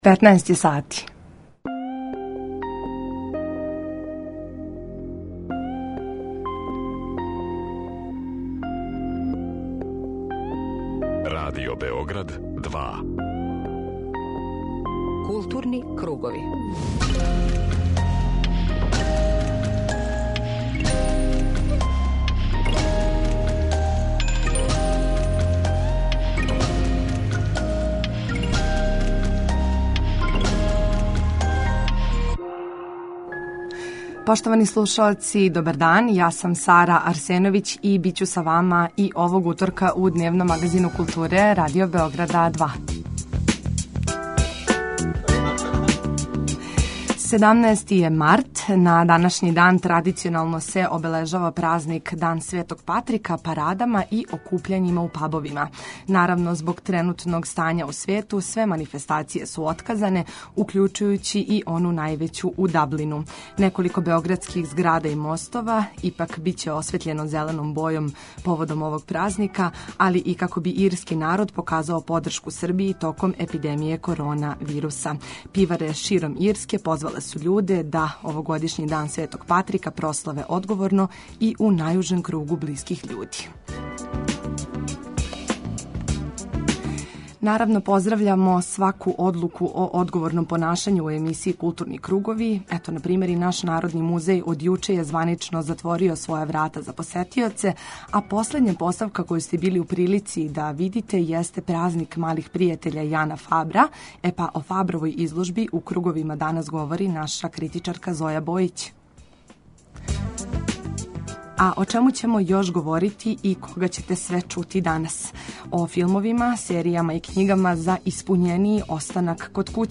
У емисији Културни кругови данас ћемо разговарати са професорком Видом Огњеновић, нашом истакнутом редитељком и књижевницом, која ће нам казати шта чита ових дана, те препоручити књижевне наслове за ванредне ситуације.